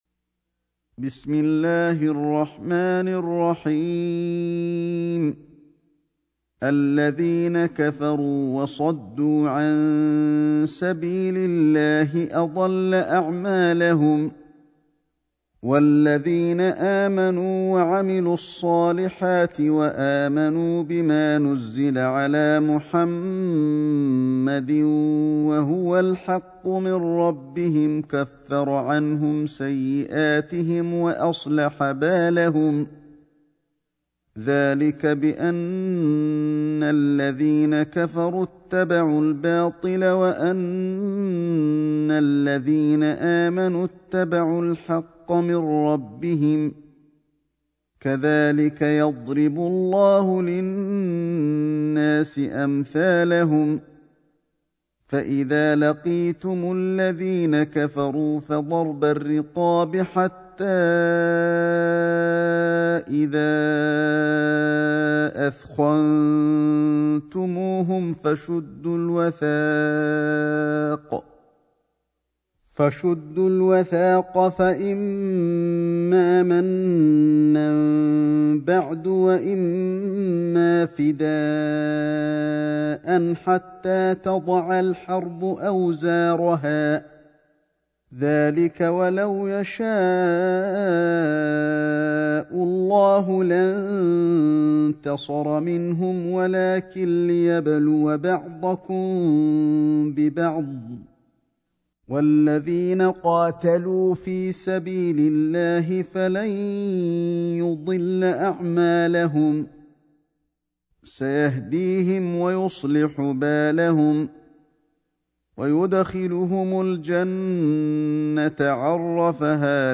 سورة محمد ﷺ > مصحف الشيخ علي الحذيفي ( رواية شعبة عن عاصم ) > المصحف - تلاوات الحرمين